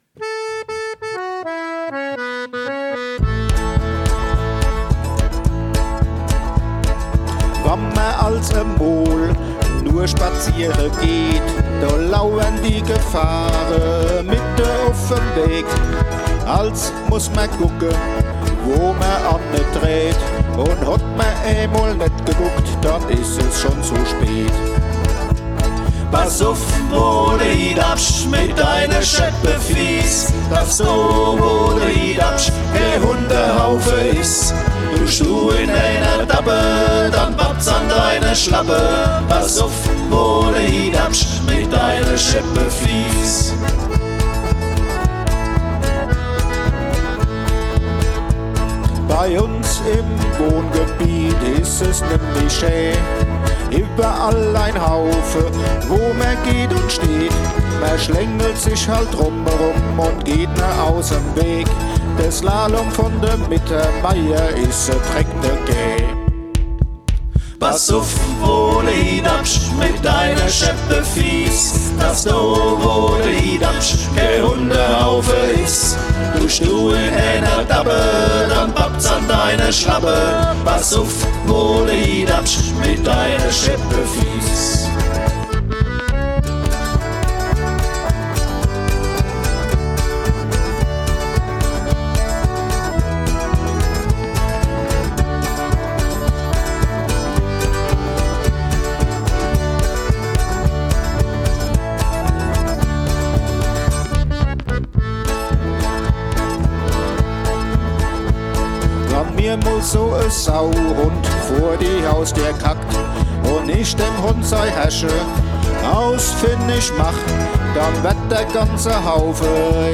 2022, Sonderkategorie: Lied, 1.